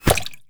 bullet_impact_water_08.wav